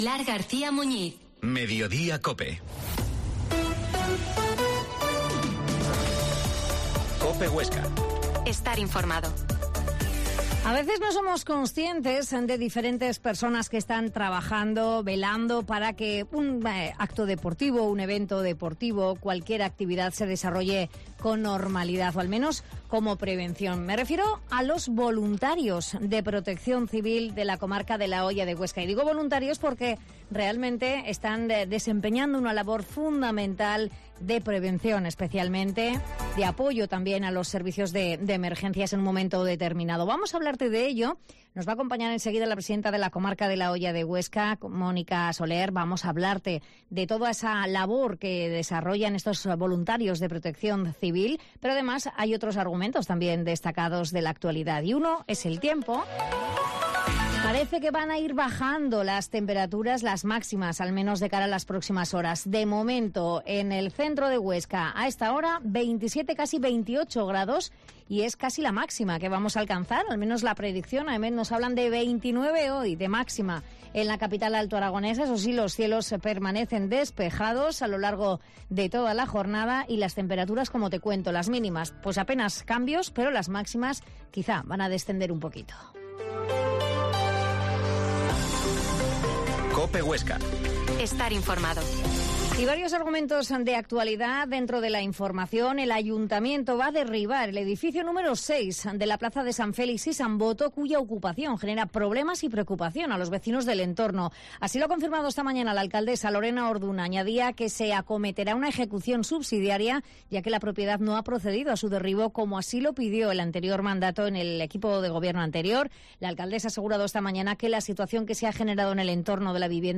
Mediodia en COPE Huesca 13.50 Entrevista a la Presidenta de la comarca de la Hoya, Mónica Soler